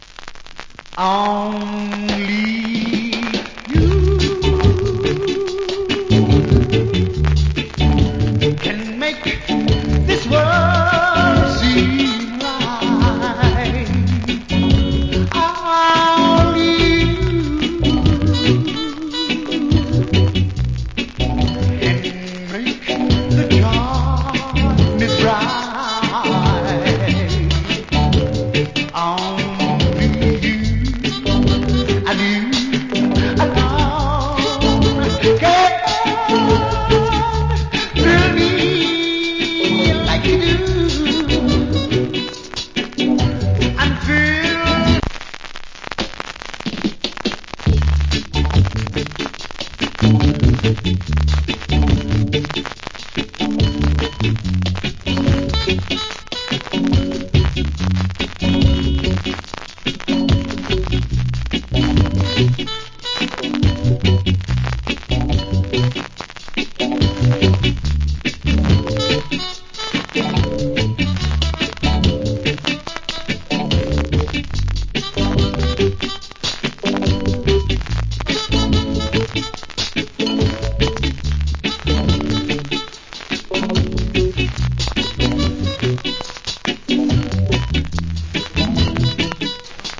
Sweet Reggae Vocal.